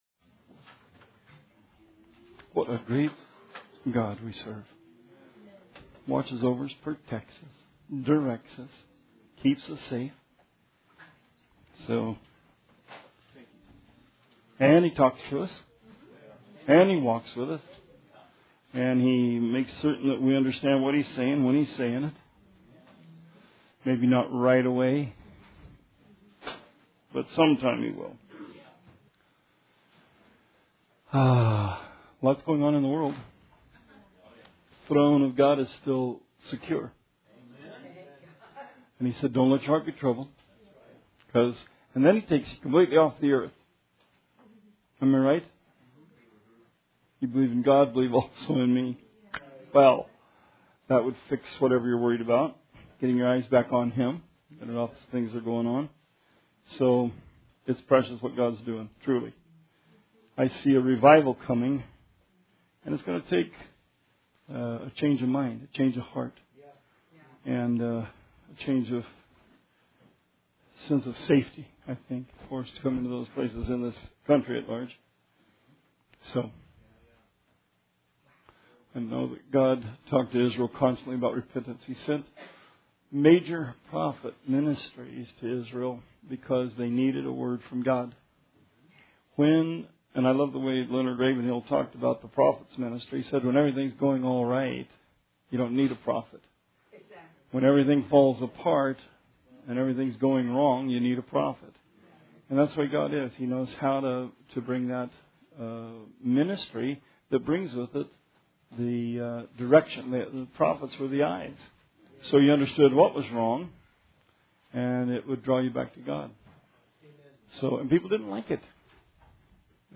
Bible Study 4/19/17